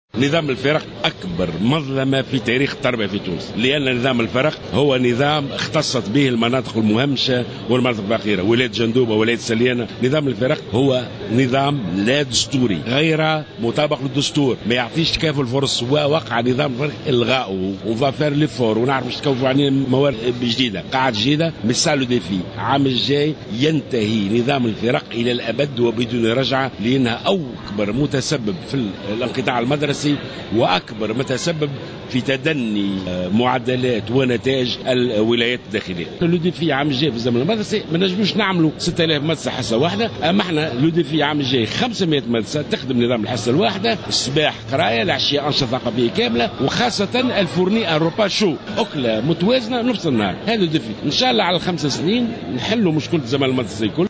وأضاف جلول في تصريح لمراسل "الجوهرة أف أم" على هامش زيارته اليوم لولاية جندوبة أنه سيتم تعميم هذا الإجراء على كافة المدراس بكامل تراب الجمهورية على امتداد السنوات الخمس القادمة.